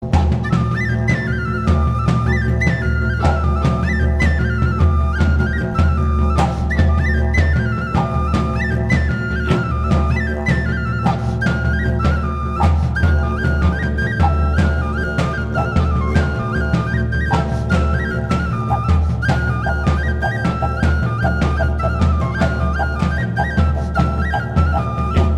Этническая музыка